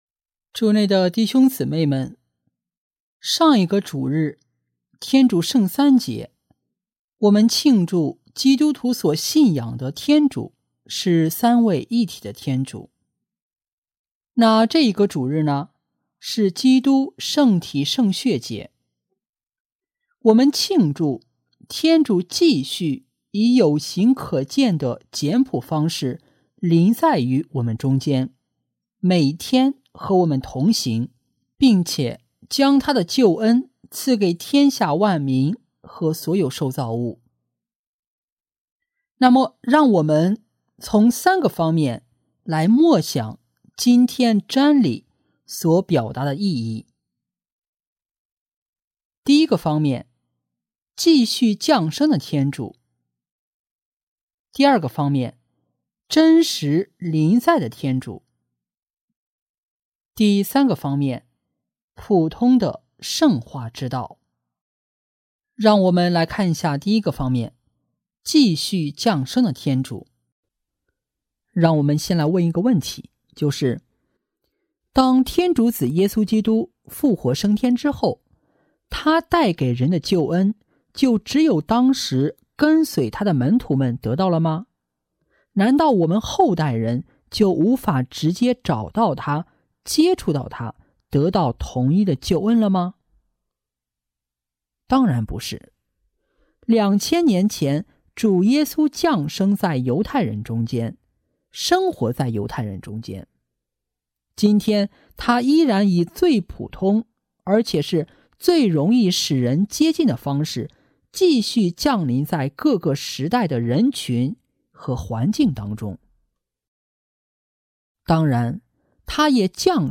【主日证道】| 天主简朴的新临在（甲-基督圣体圣血节）